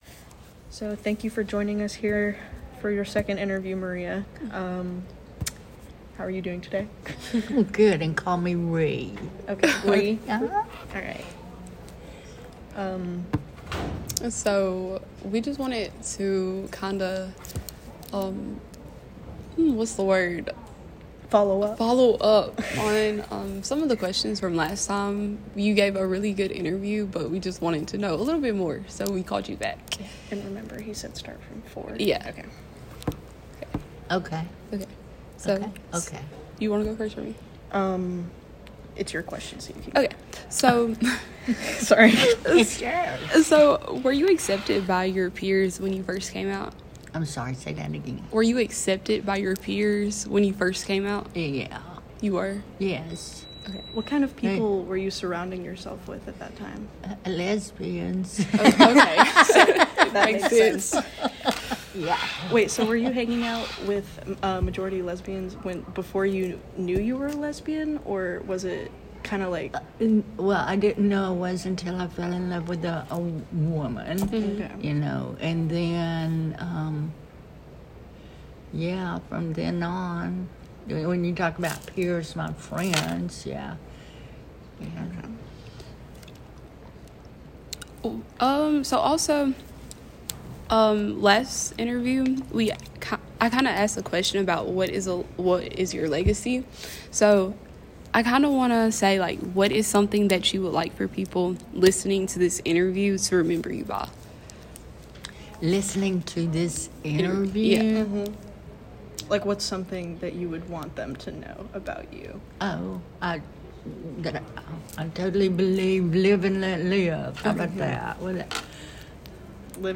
oral history interview
It is being conducted on April 5 at Starbucks in Tuscaloosa, Alabama, and concerns her recollections and experiences of realizing that she was a lesbian and meeting her current wife.